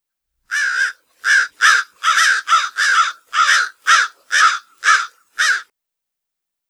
animals / crows